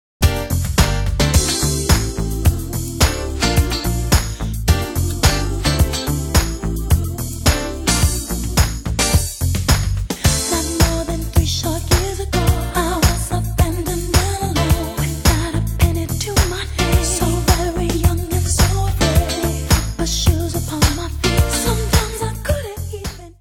Change keys [without effecting the speed]
original key  +2 semitones